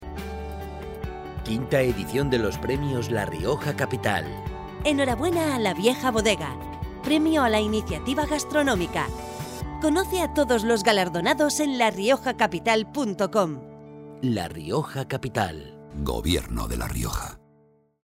Versión 1 de cuña de 20".